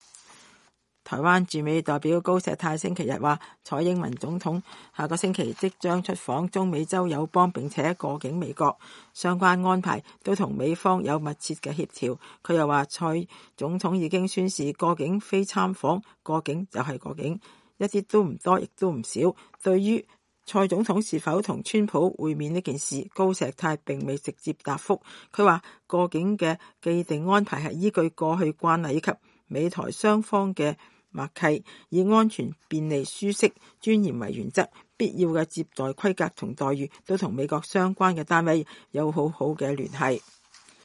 台灣駐美代表高碩泰在元旦升旗儀式中致辭